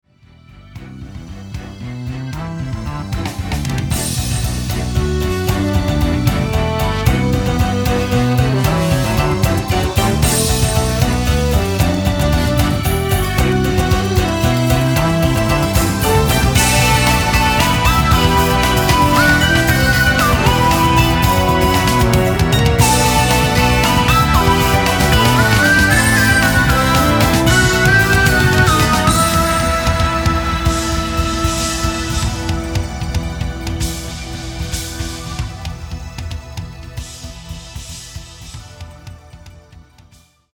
和洋折衷ジャズ＆ロック、